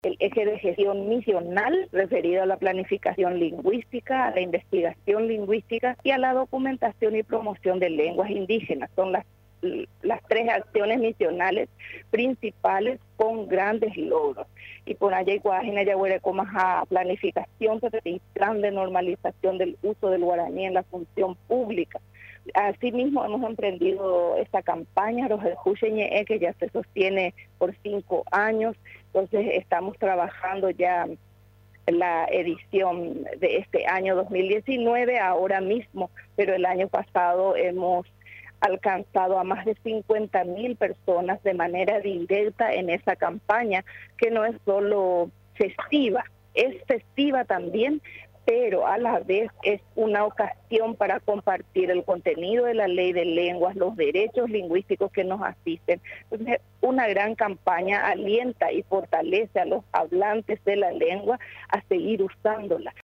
La campaña Rohendu che ñe´e, llegó a más de 50.000 paraguayos, quienes compartieron el contenido de la Ley de Lenguas, resaltó este jueves, durante el informe de gestión, la ministra Ladislaa Alcaraz.